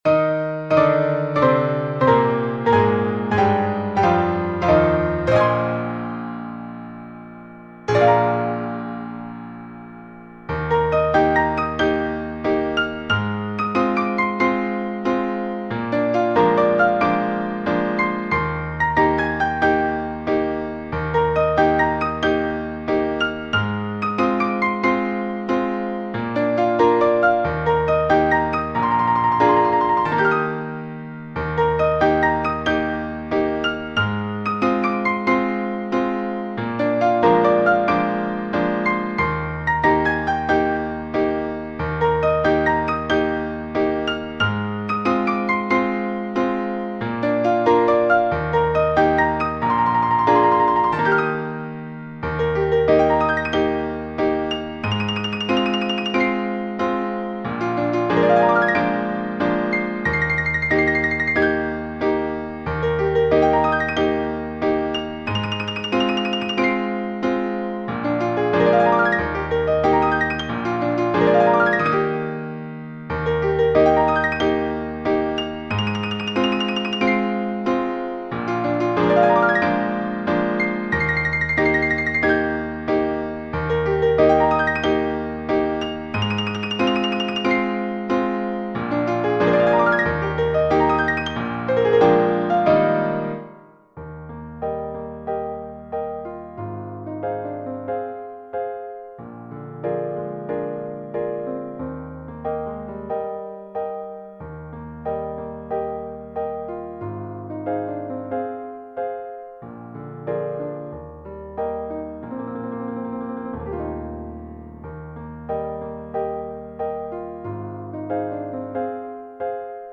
クラシック音楽
乙女の祈り　ピアノ楽譜　ドレミふりがな　バダジェフスカ　The Maiden's Prayer　Badarzewska　La prière d'une vierge-piano 　初心者でも弾けるピアノ曲